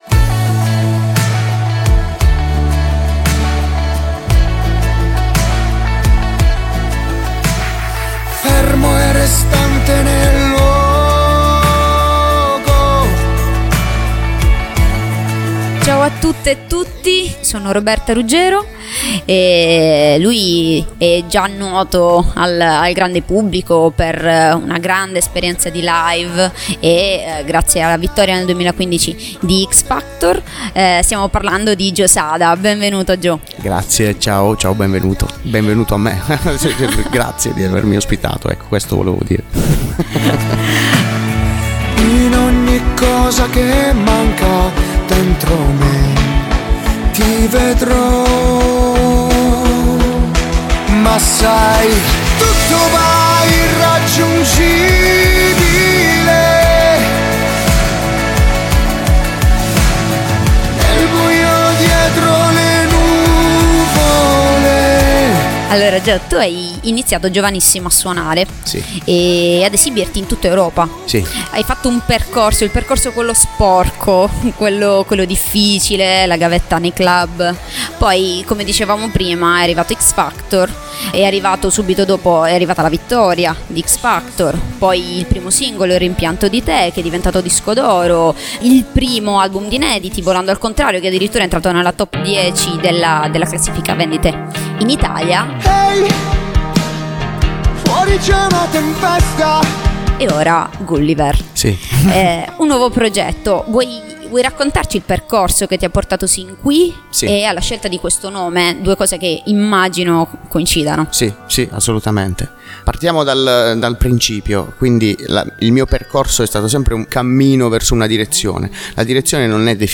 Scopriamo insieme qualcosa in più su Gulliver, il nuovo progetto del cantautore barese. L’intervista